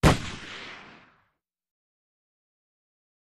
Automatic Weapon 4, Single & Multiple Bursts, Echoey.